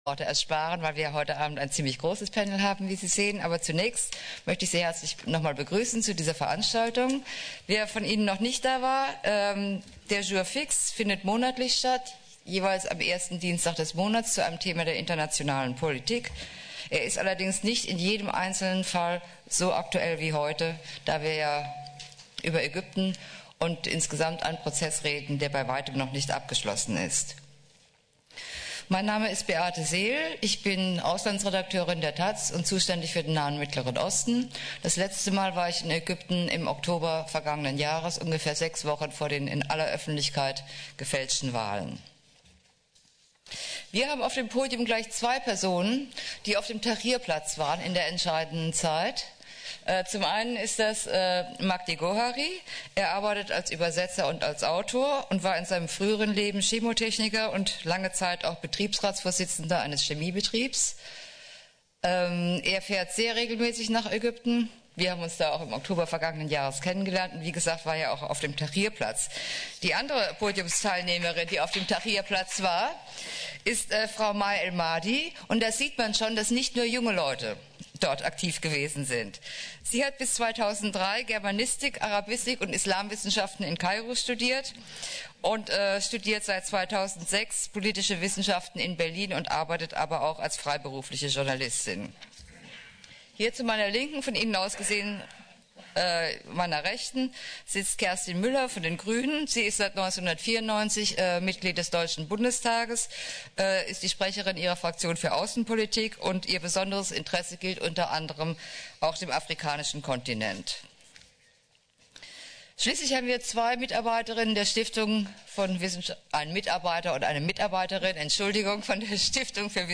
Audiomitschnitt: Die unvollendete Revolution in Ägypten – Demokratischer Aufbruch in der Region Nahost?
Podiumsdiskussion mit Kerstin Müller